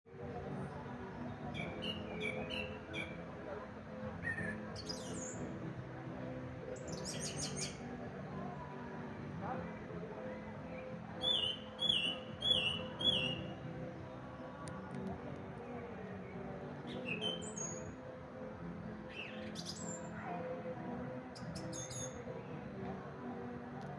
Besonders schön an dem Abend sang die Singdrossel.
Singdrossel.mp3